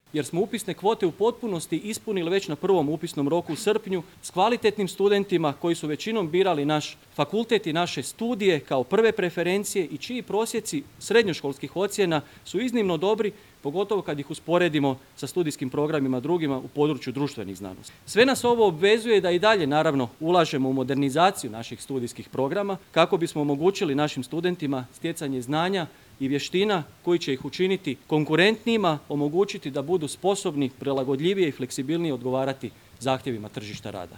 ZAGREB - Fakultet političkih znanosti obilježio je 63. obljetnicu svog rada i djelovanja u novim obnovljenim prostorijama u središtu Zagreba.